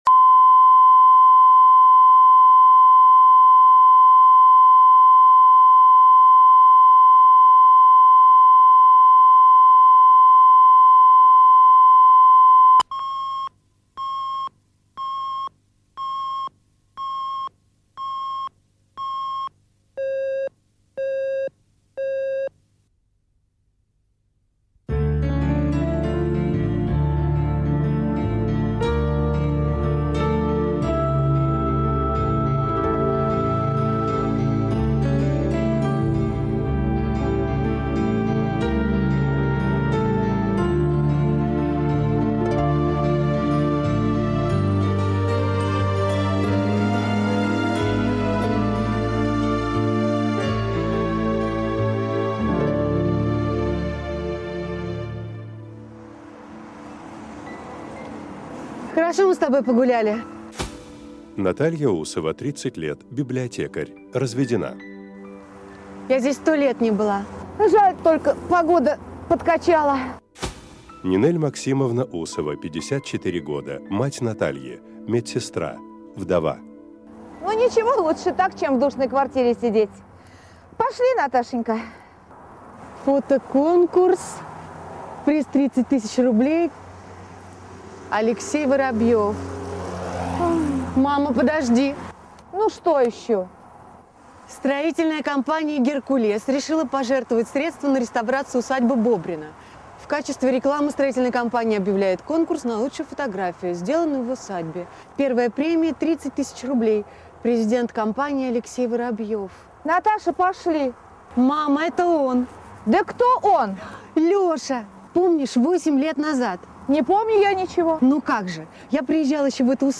Аудиокнига В ожидании принца | Библиотека аудиокниг